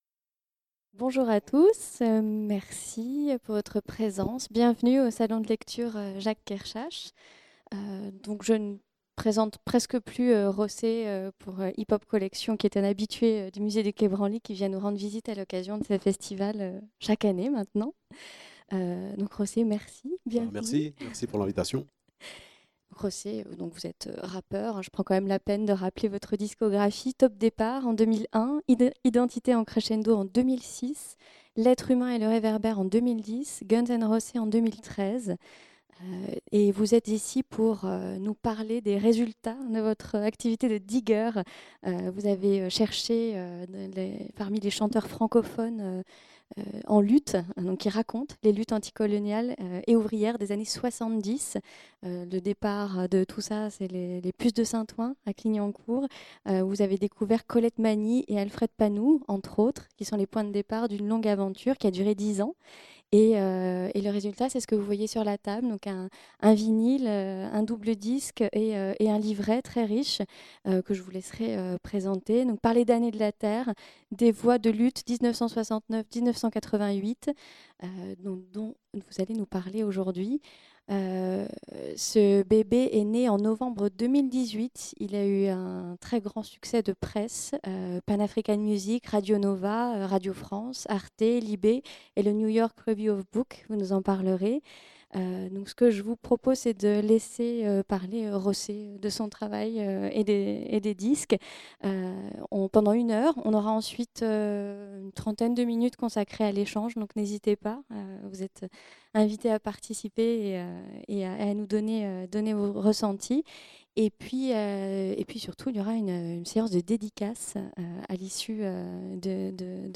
Lieu : Salon de lecture Jacques Kerchache